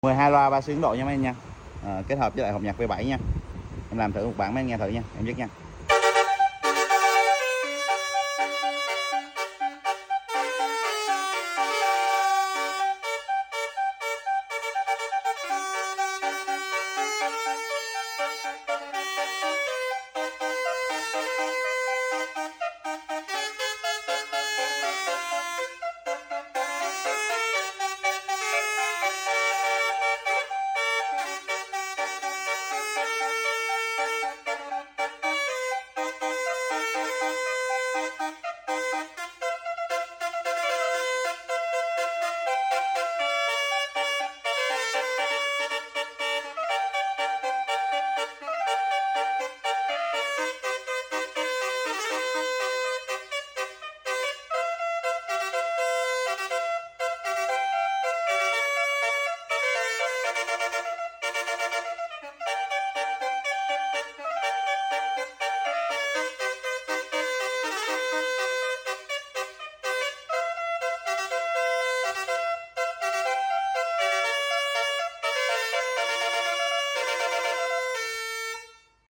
12loa basuri ấn độ mẫu sound effects free download